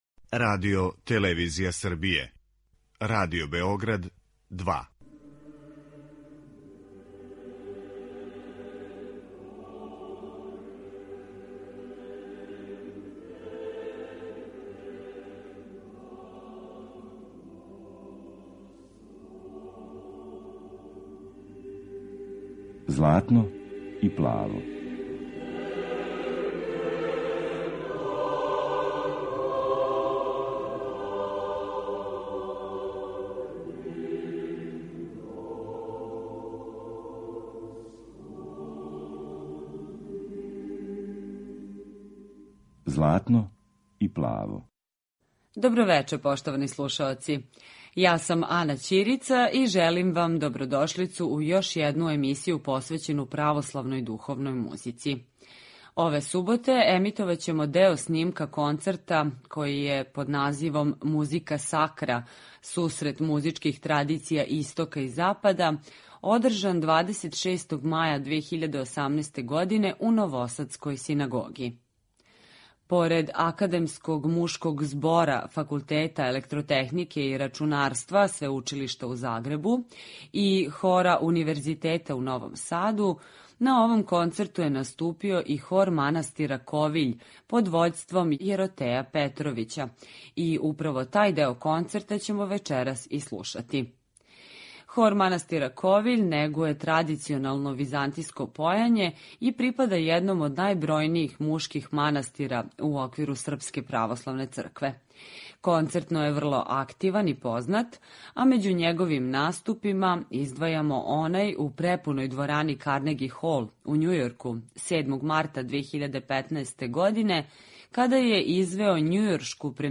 Концерт „Musica Sacra”
Емитујемо снимак са концерта који је 26. маја 2018. године одржан у новосадској синагоги. Поред Академског мушког збора Факултета електротехнике и рачунарства Свеучилишта у Загребу и Хора Универзитета у Новом Саду, тада је наступио и Хор манастира Ковиљ под вођством Јеротеја Петровића, данас викарног епископа топличког.